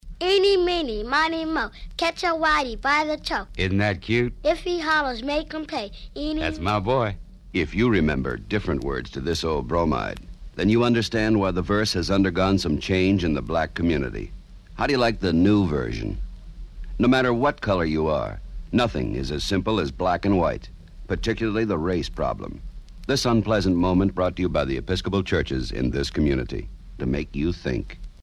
Title churchawakens362 Description "Eenie Meenie," a a public service radio announcement produced by the Executive Council of the Episcopal Church, turns the tables on a racist childhood chant.